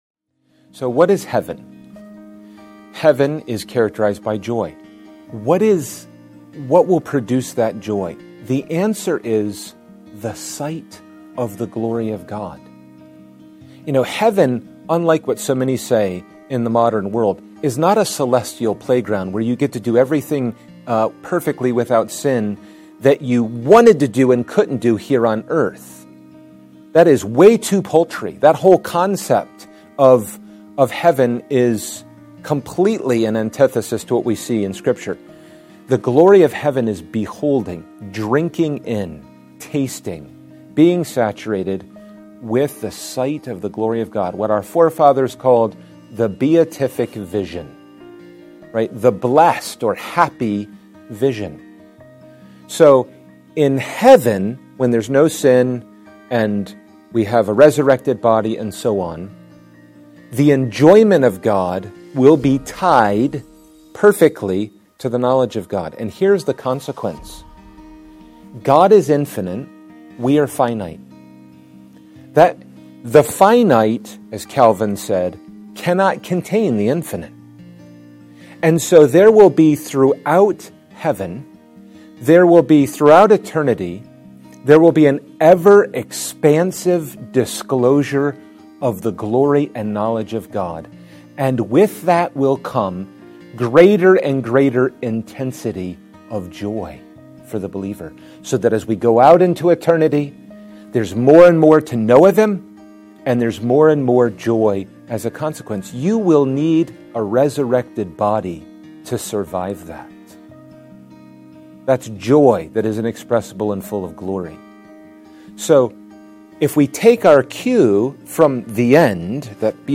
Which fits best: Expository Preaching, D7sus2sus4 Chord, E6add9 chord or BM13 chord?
Expository Preaching